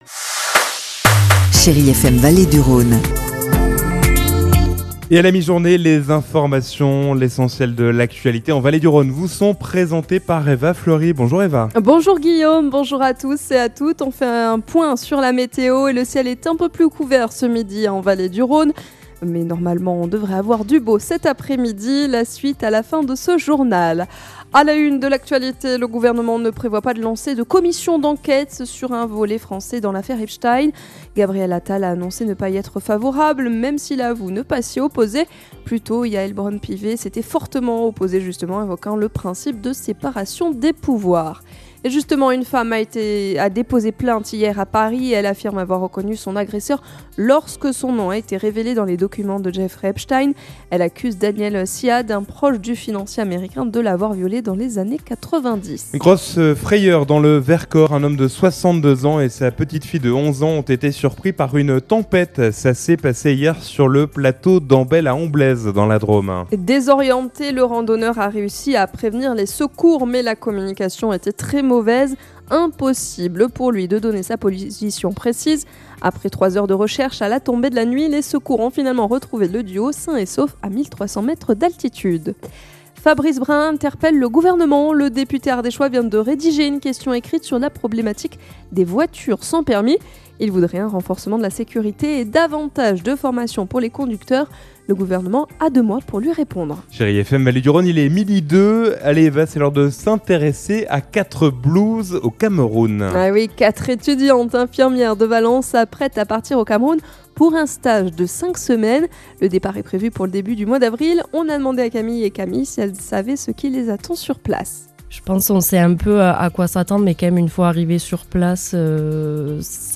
Mercredi 11 février : Le journal de 12h